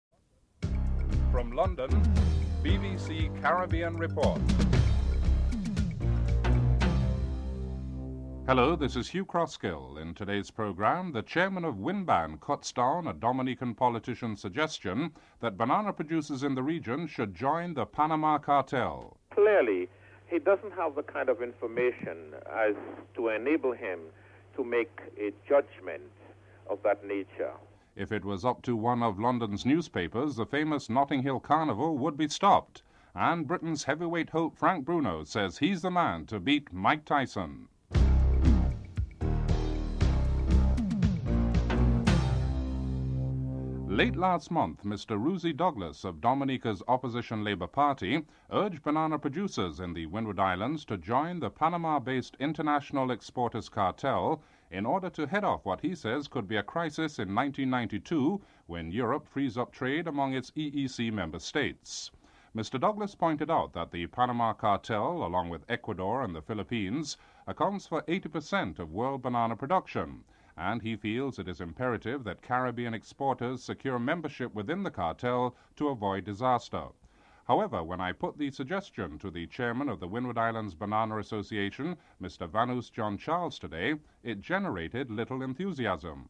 4. Daily Financial Report: (07:05-08:06)